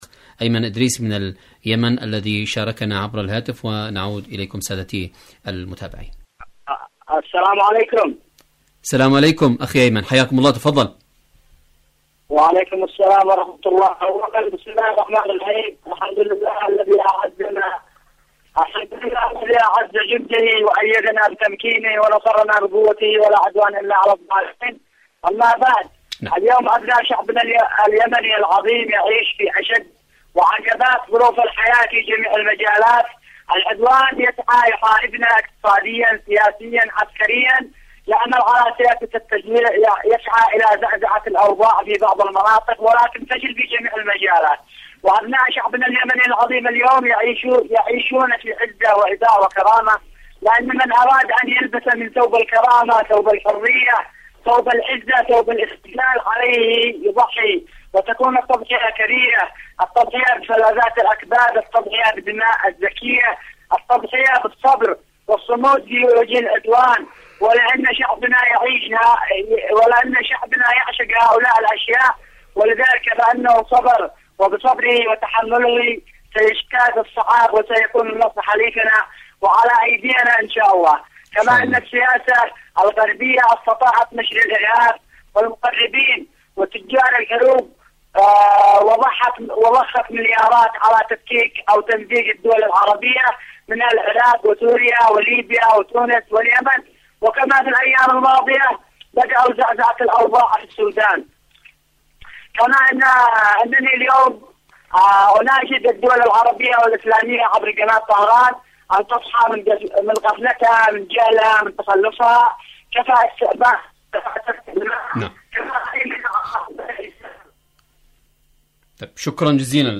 اليمن التصدي والتحدي /مشاركة هاتفية